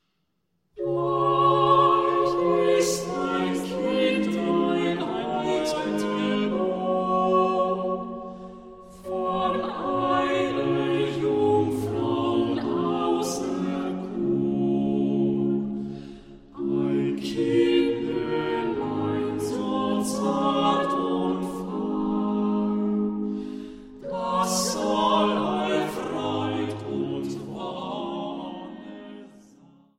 Leitung und Posaune